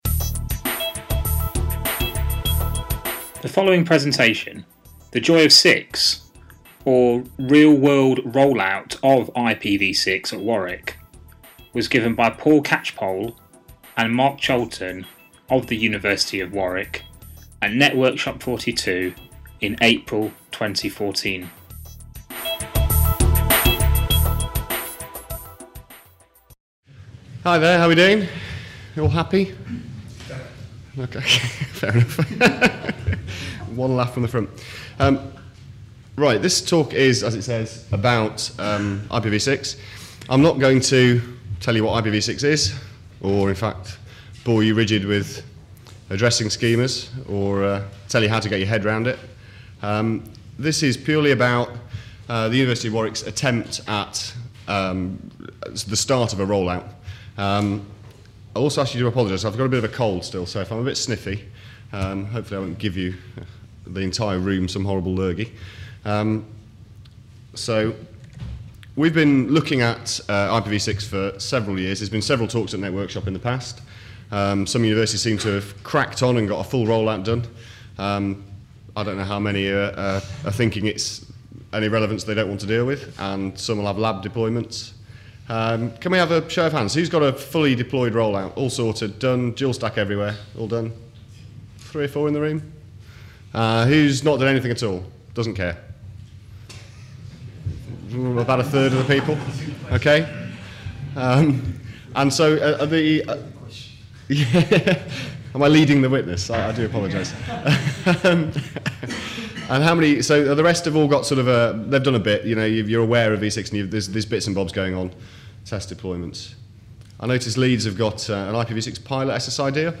Networkshop 42